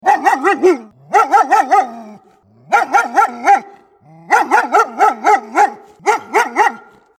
German Shepherd Barking Bouton sonore